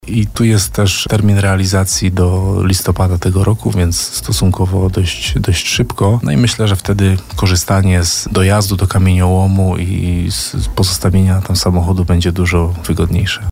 Jak mówi wójt Jacek Kaliński, oferty już zostały otwarte, trwa ich analiza, a umowy z wykonawcami - każda z dwóch części zadania będzie miała swojego - mają zostać podpisane lada moment.